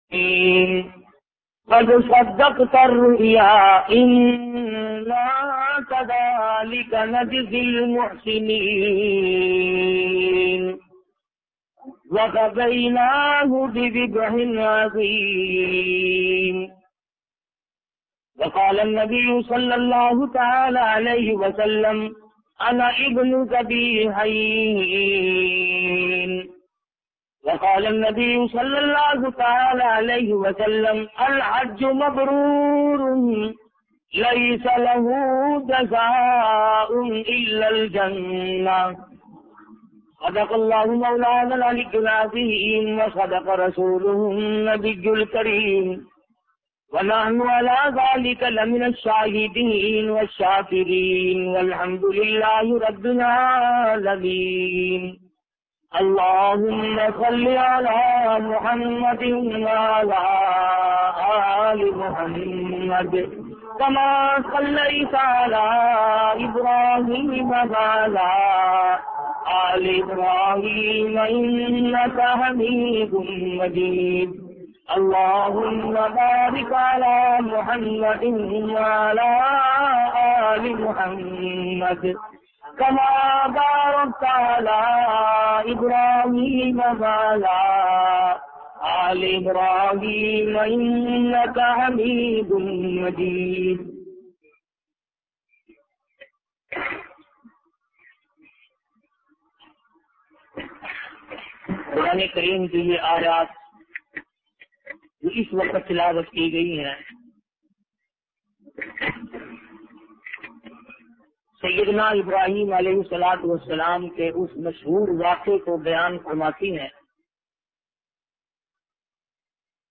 hajj bayan